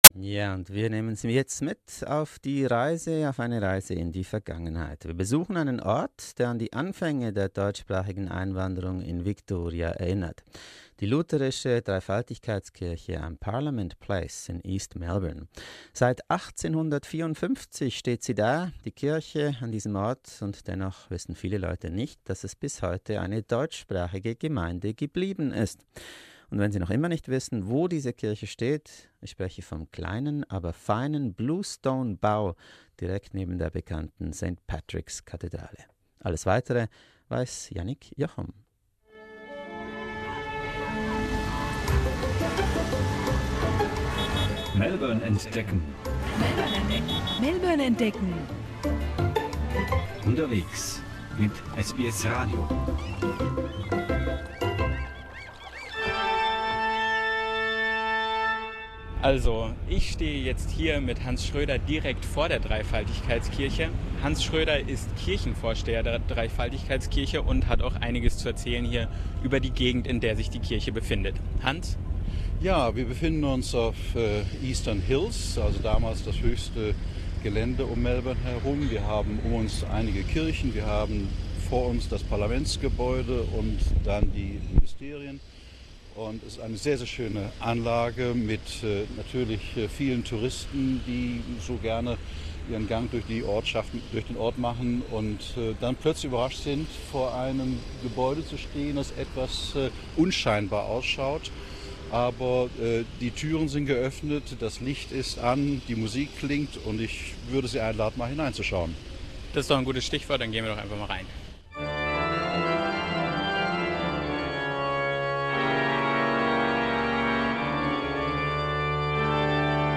reportage-dreifaltigkeit.Mp3